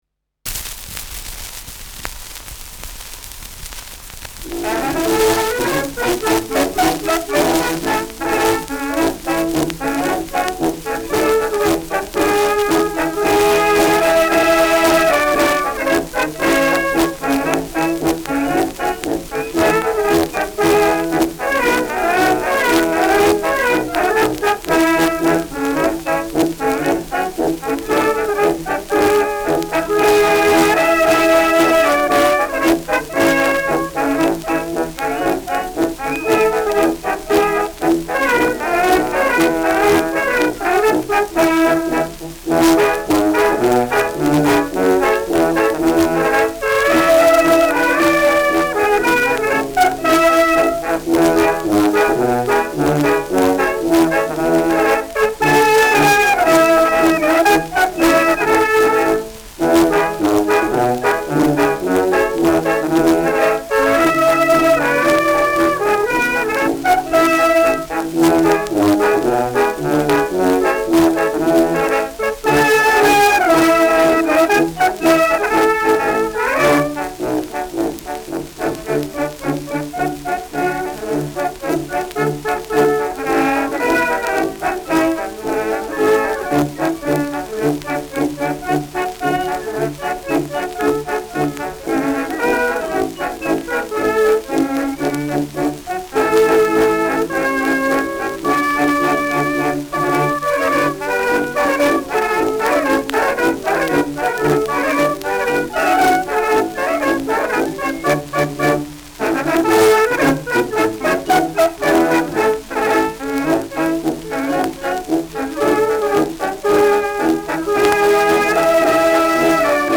Schellackplatte
[Berlin?] (Aufnahmeort)